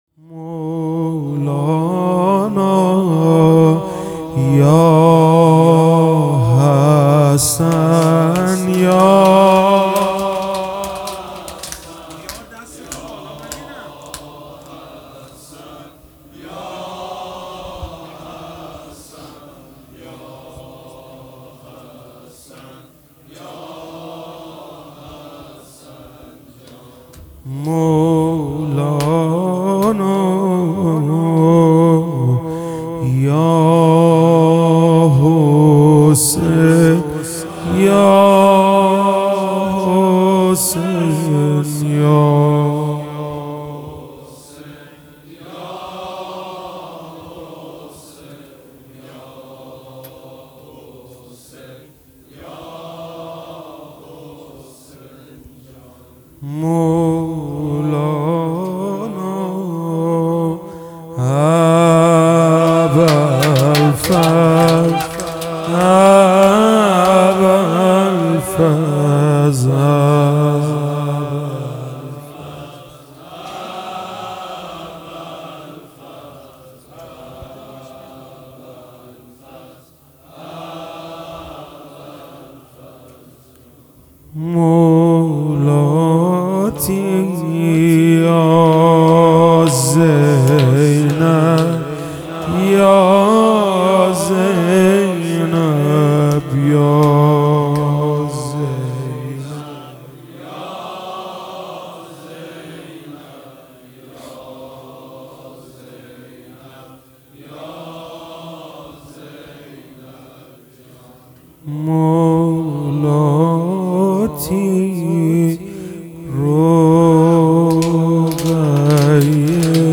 شب دوم فاطمیه1400